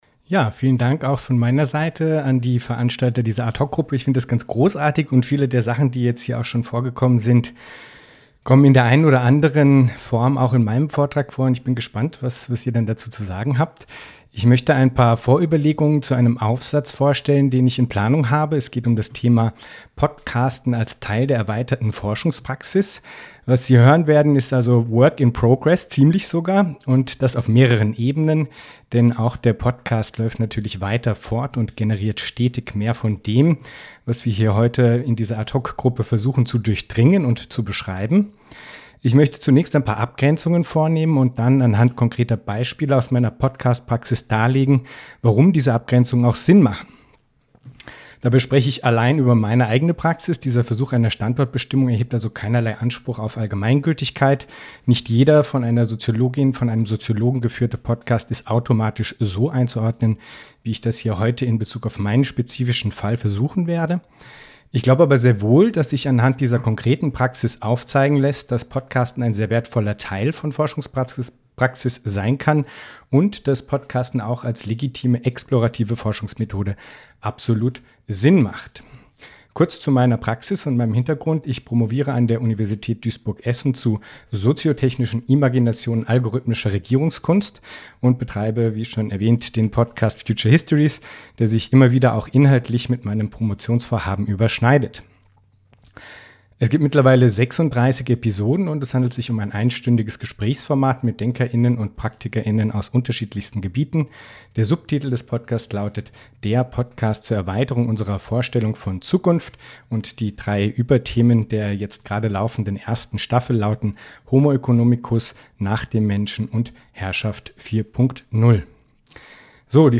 Vortrag
beim DGS Kongress 2020 zum Podcasten als erweiterte Forschungspraxis